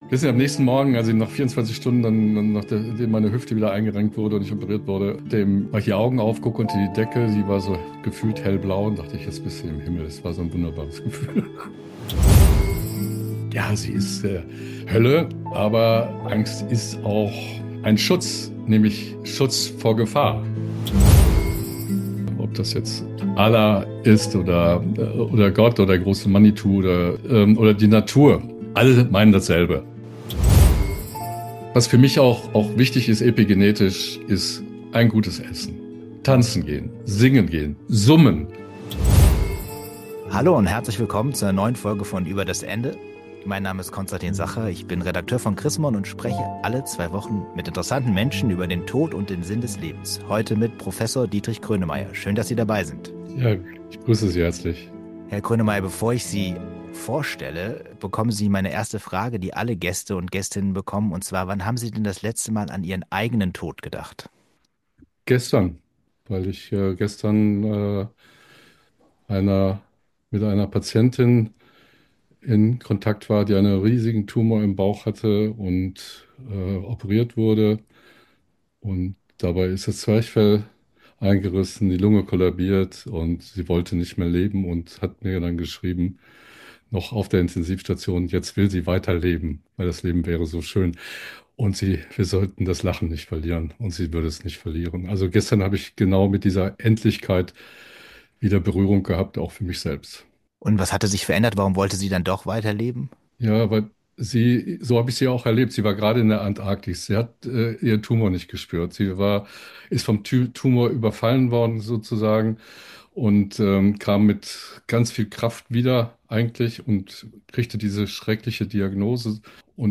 Gespräche über Tod, Sterben und Sinn des Lebens